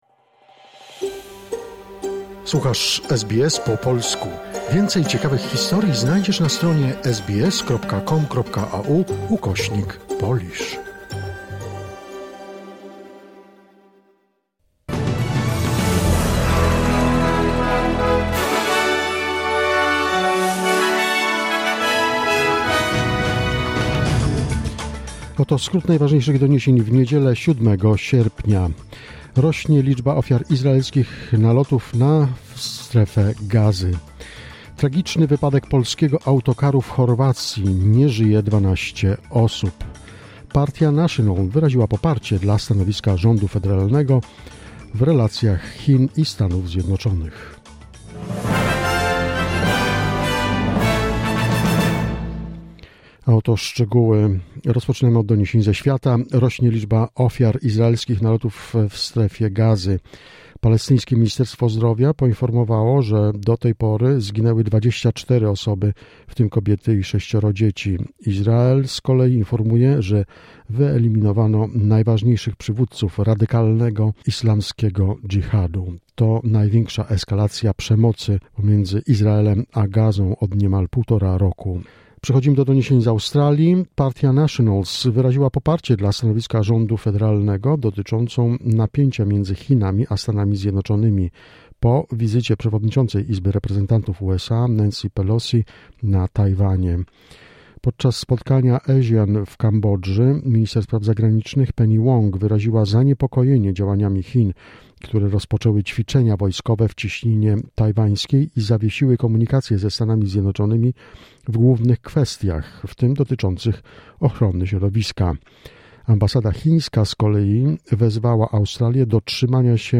SBS News in Polish, 7 August 2022